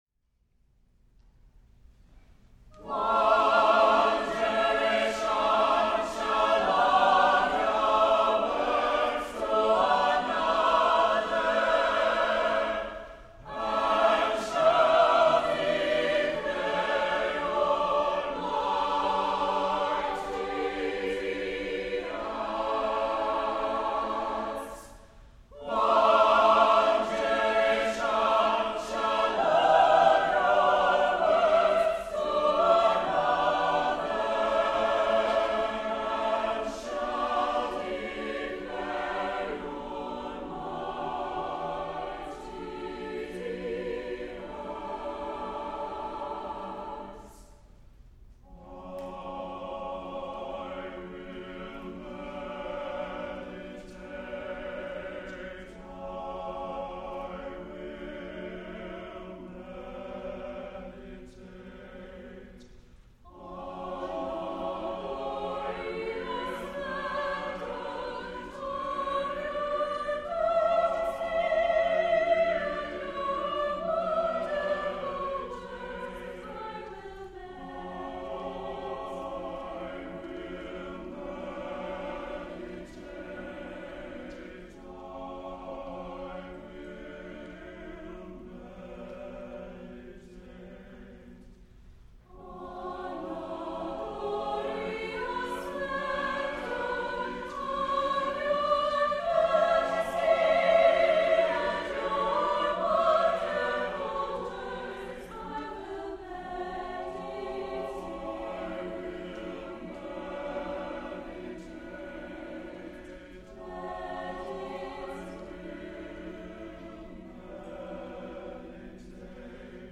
SSAATTBB